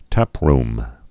(tăprm, -rm)